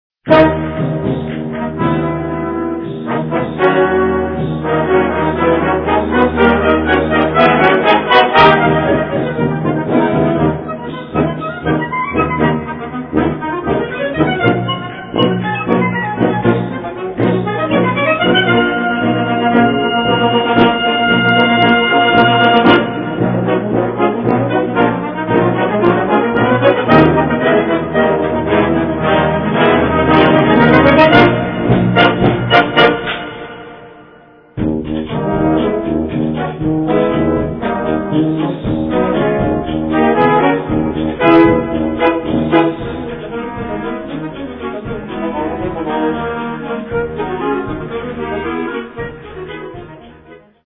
Gattung: Konzertstück
A4 Besetzung: Blasorchester Zu hören auf